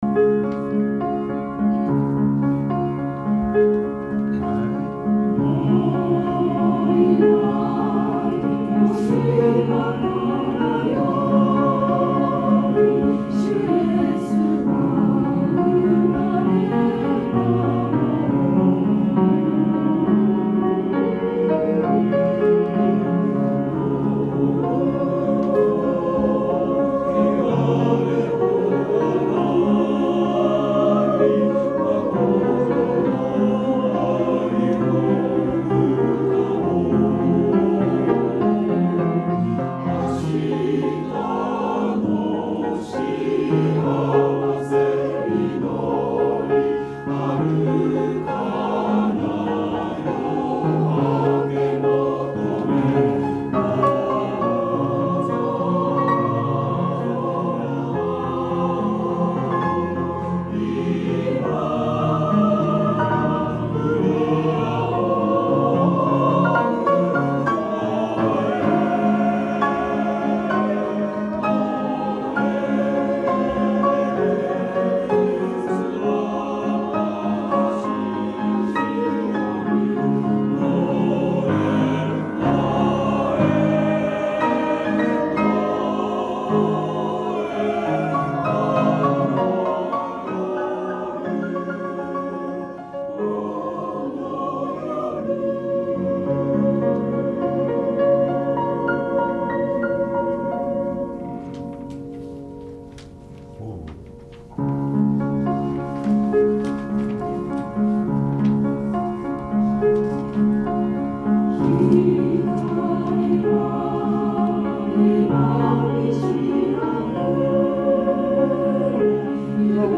練習場所：アスピア明石北館　8階学習室801A・B（明石市）
出席者：31名（sop13、alt8、ten5、bass5）